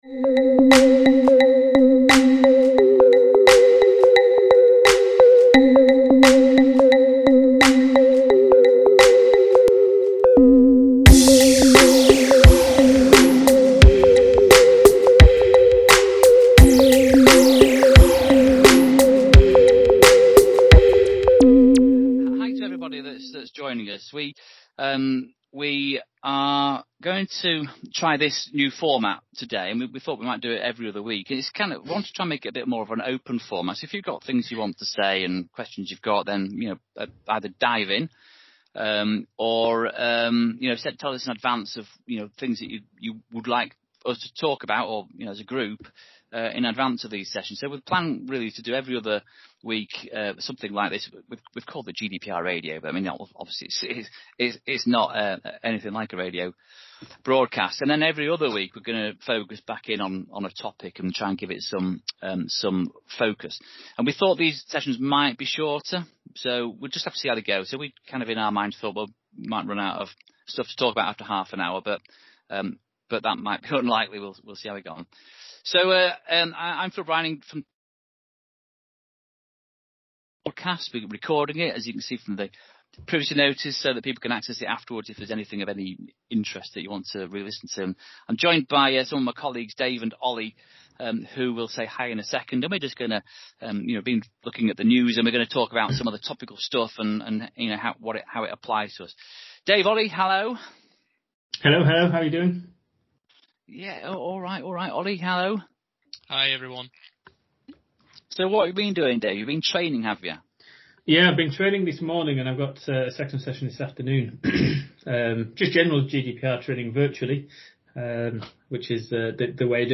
During these new sessions, we will discuss recent news, share our views, and we will also be accepting support questions to handle live.